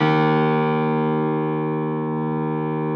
53g-pno03-D0.wav